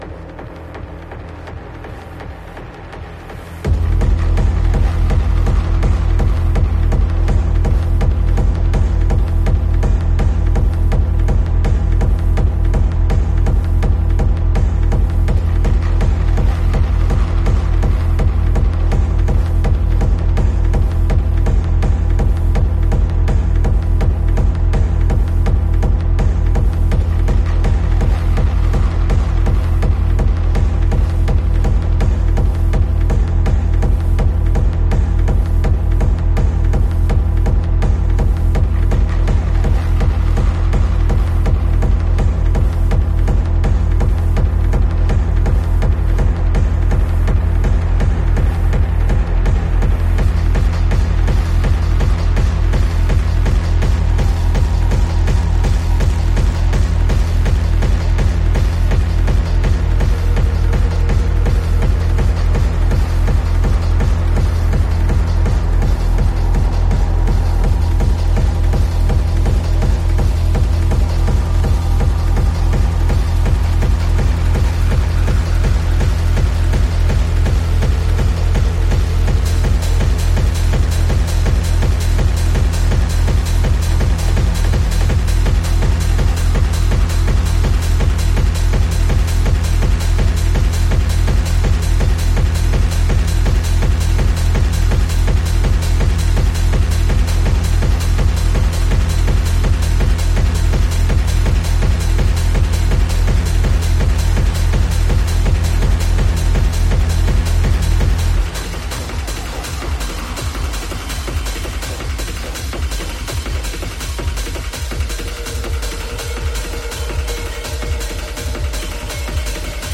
Fierce pounding big room raving techno tracks!
Techno